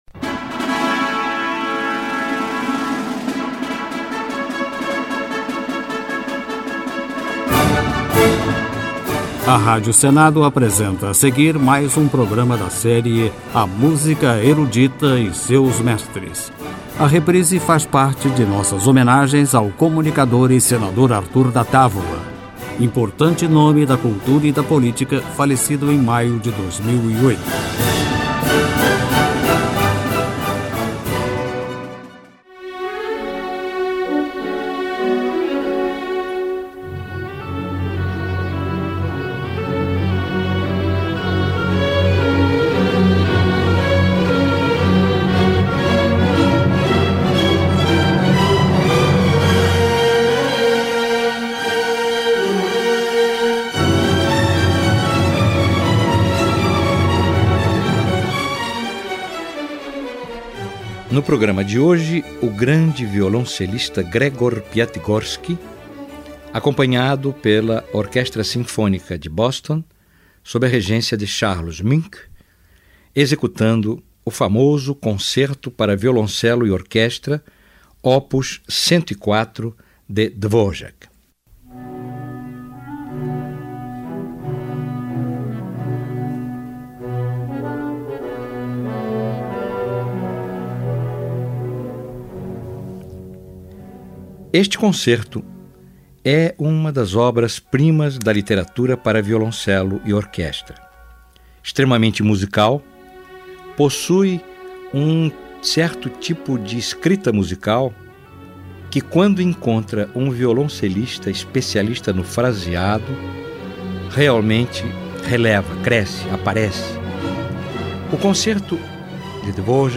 Concerto para Violoncelo e Orquestra em Si Menor, Opus 104, de Antonin Dvorak, com a Orquestra Sinfônica de Boston, com o violoncelista Gregor Piatigorsky, maestro: Charles Munch.